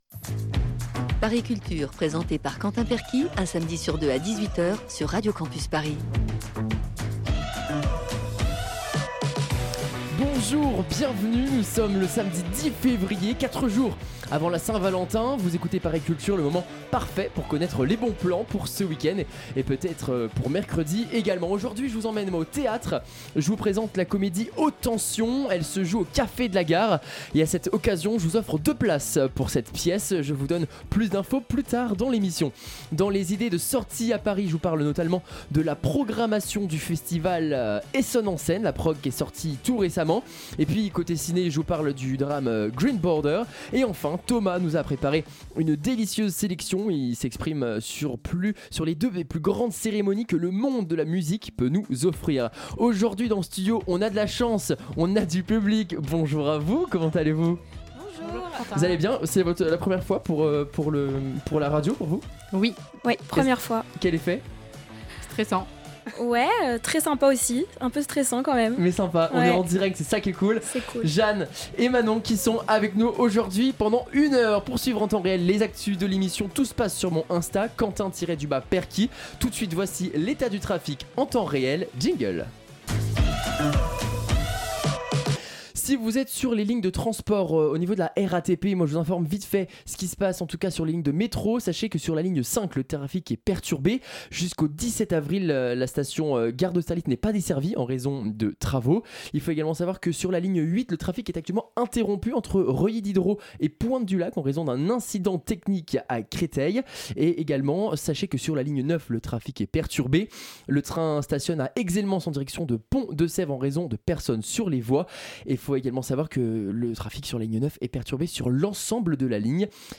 Magazine Culture